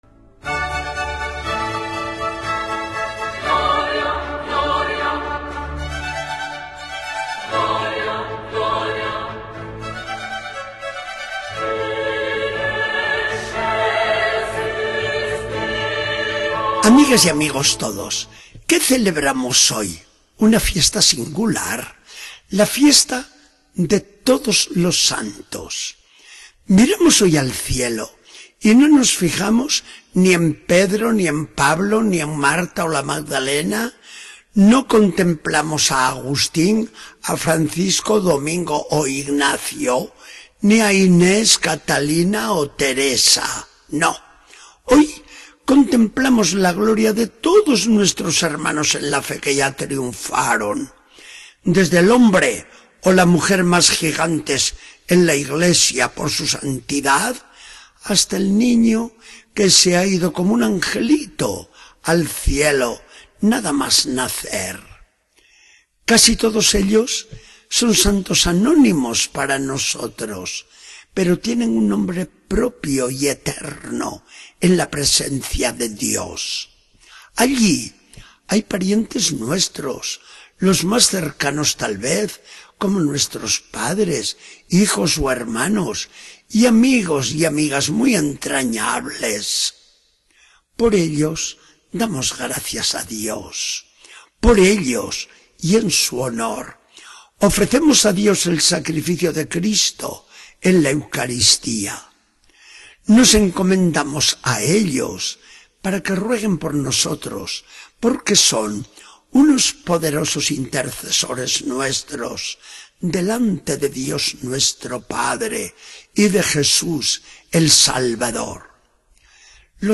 Charla del día 1 de noviembre de 2014. Del Evangelio según San Mateo 4, 25-5, 12.